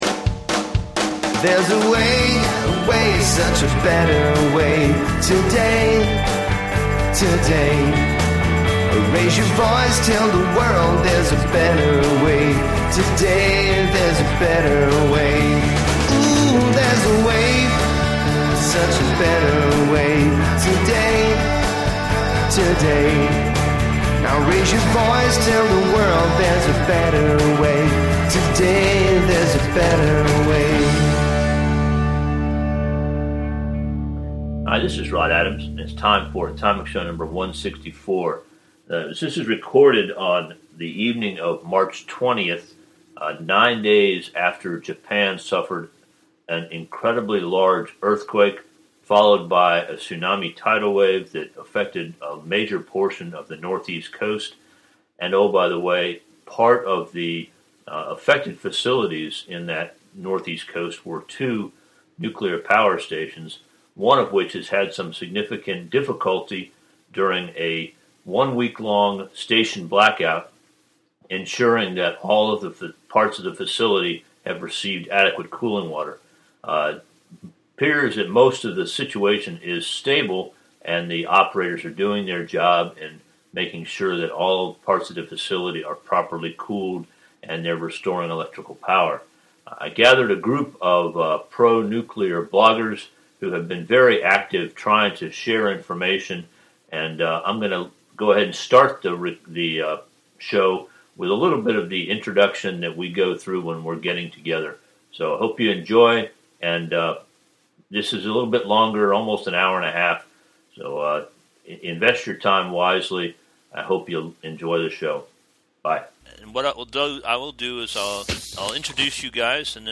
Atomic Show #164 - Fukushima Discussion by Pro Nuclear Communicators - Atomic Insights
On Sunday, March 20, a group of pro nuclear energy communicators gathered to chat about the events at the Fukushima Daiichi nuclear power station following a devastating earthquake and tsunami that put the entire station into a blackout condition. As predicted at the very beginning of the casualty, there have been no releases of radioactive material from the station in quantities high enough to harm human health.